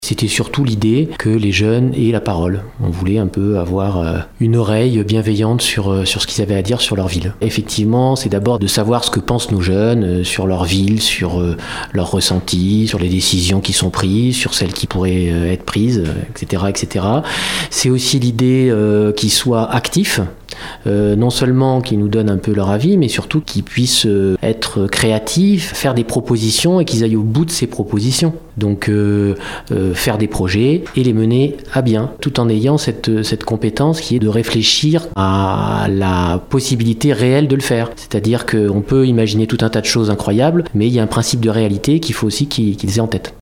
Un acte citoyen et un réel engagement pour ces jeunes, comme le souligne Laurent Rouffet, adjoint au maire en charge de l’Education :